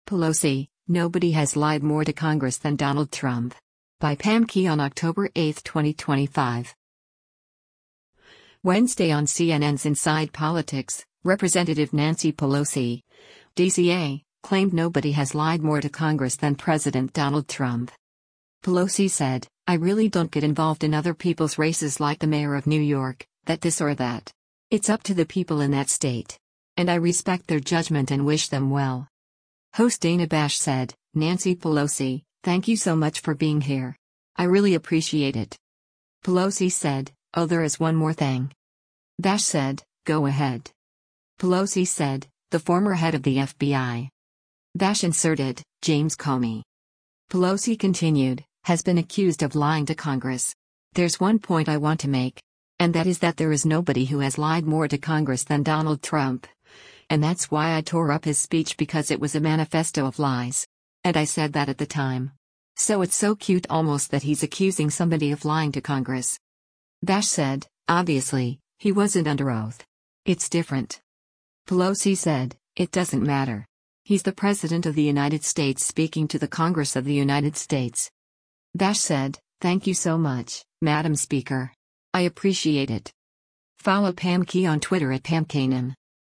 Wednesday on CNN’s “Inside Politics,” Rep. Nancy Pelosi (D-CA) claimed nobody “has lied more to Congress” than President Donald Trump.